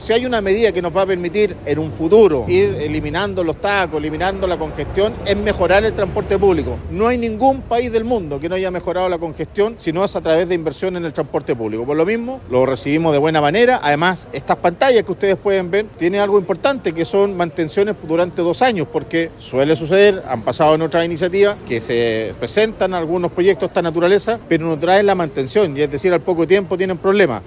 El alcalde de Talcahuano, Eduardo Saavedra, señaló que los paneles tendrán mantención constante para su correcto funcionamiento.
cuna-alcalde.mp3